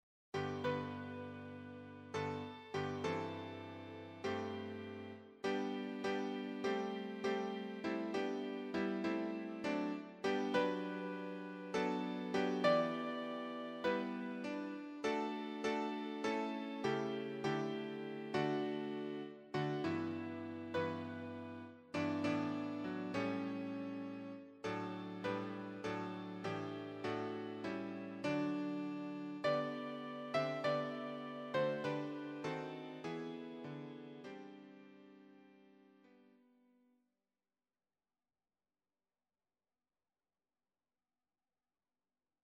choir SATB
Electronically Generated
Sibelius file